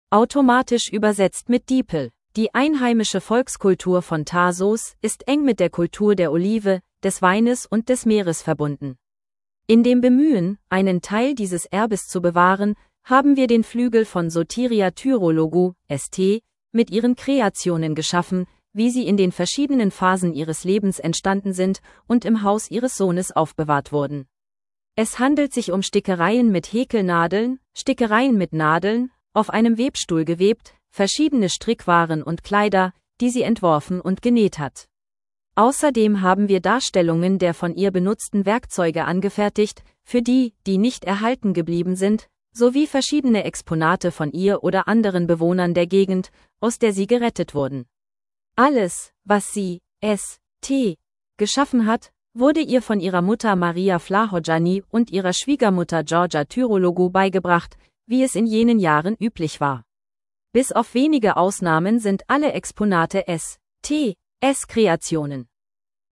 Geführte Audio-Tour